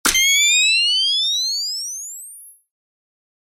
Звуки прибора ночного видения